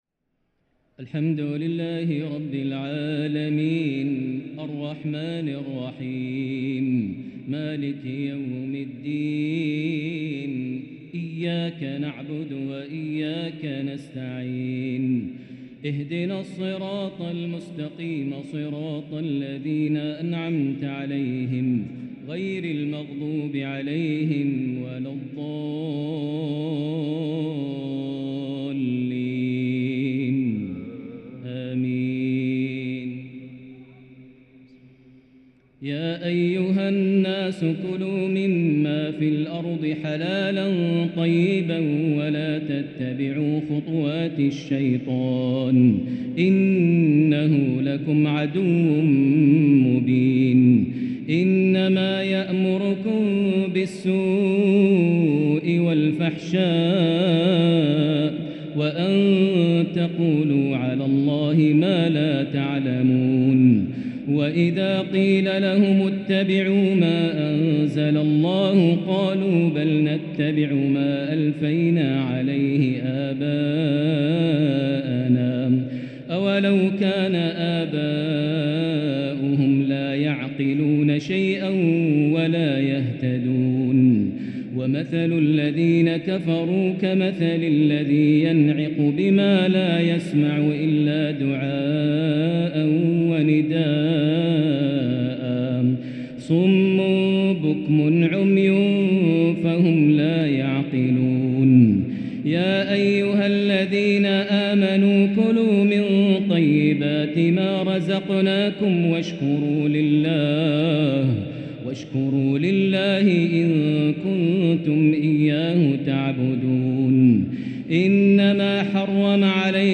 تراويح ليلة 2 رمضان1444 هـ من سورة البقرة (168-203) > تراويح 1444هـ > التراويح - تلاوات ماهر المعيقلي